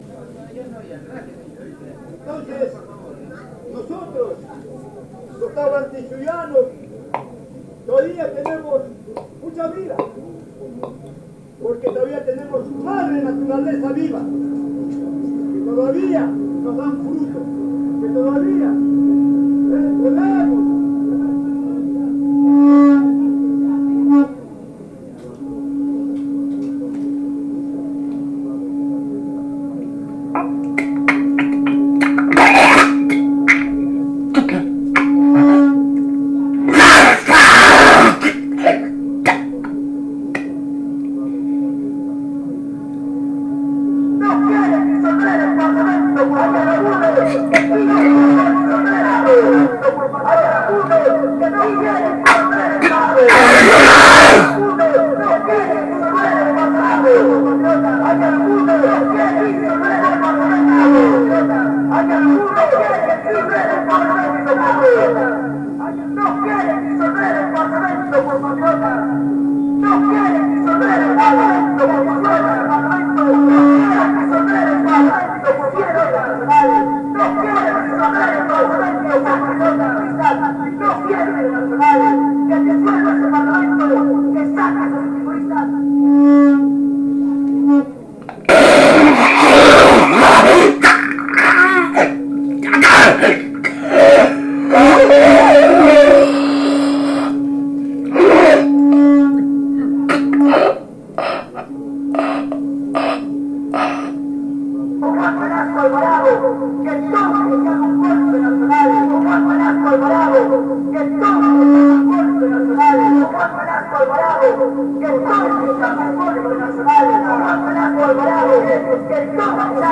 Lima protest reimagined